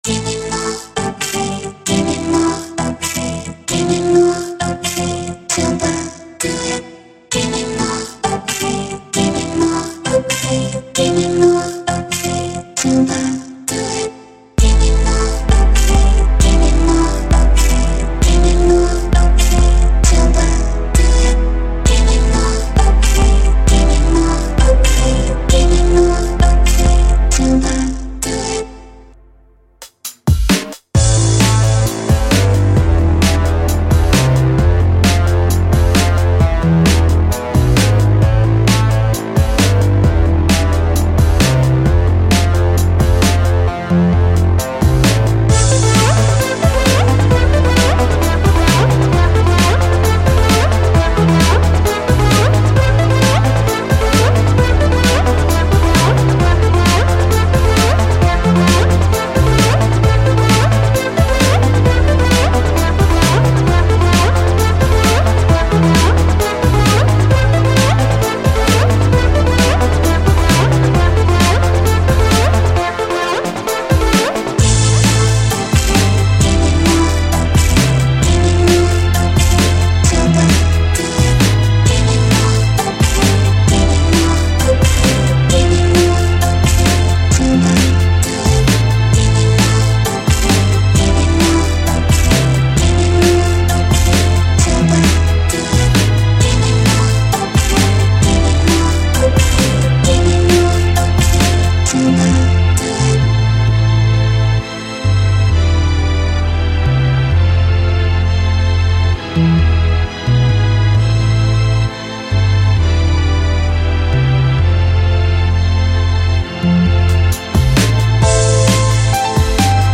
Žánr: Electro/Dance